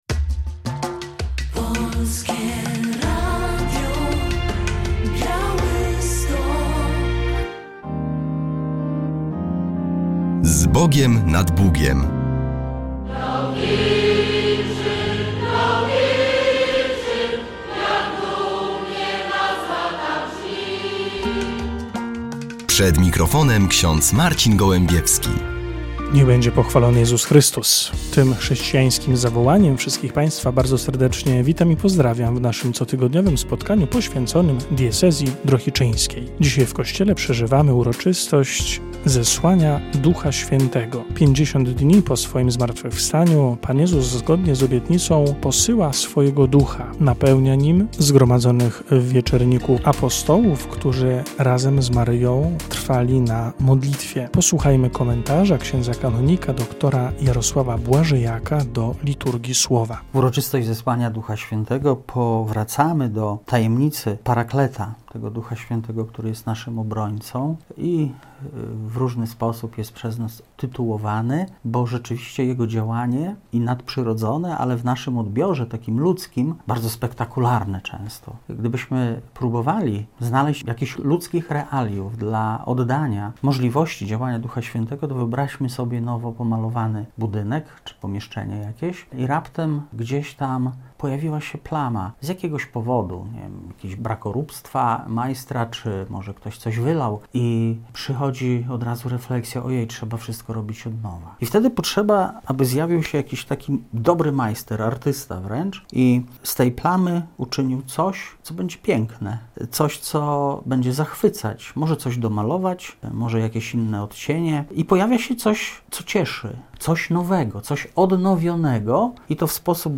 W audycji relacja z pierwszego w tym roku Nabożeństwa Fatimskiego.